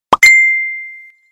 Catégorie SMS